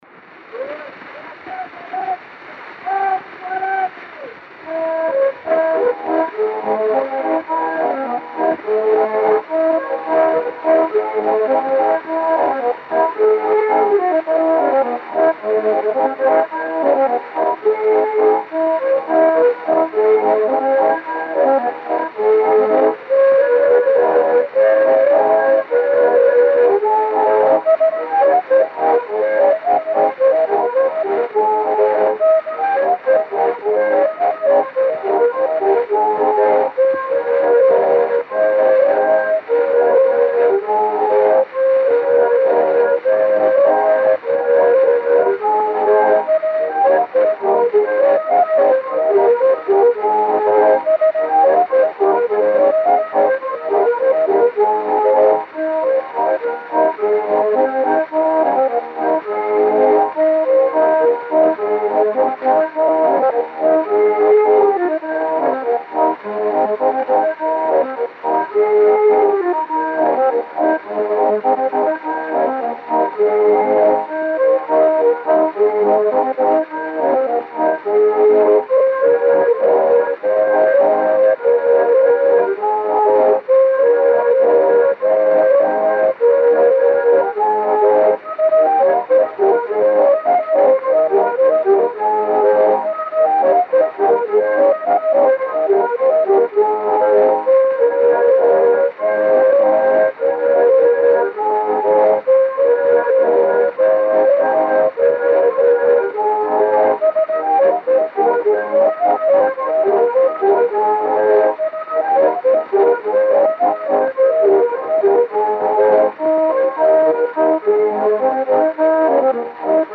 O gênero musical foi descrito como "Polka (gaita)".